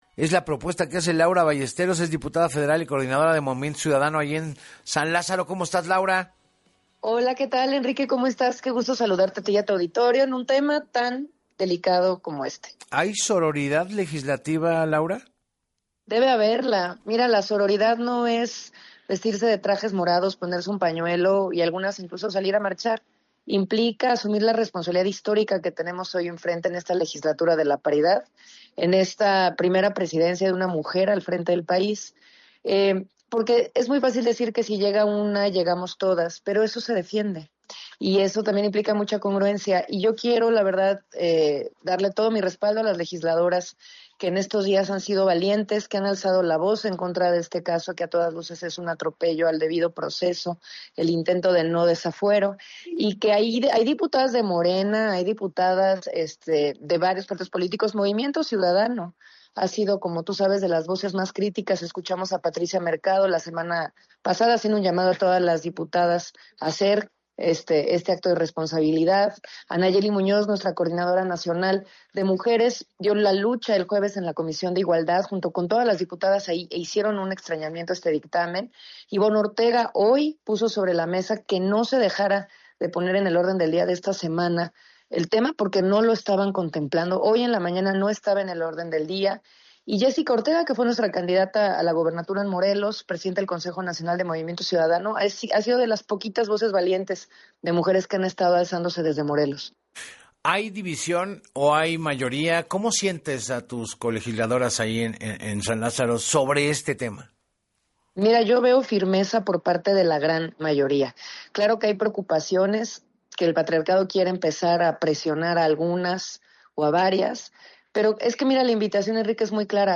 En entrevista para Así Las Cosas con Enrique Hernández Alcázar, la diputada por Movimiento Ciudadano, Laura Ballesteros, destacó que si todas las legisladoras votan a favor se podrá dar marcha atrás al dictamen que desecha el desafuero.